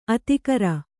♪ atikara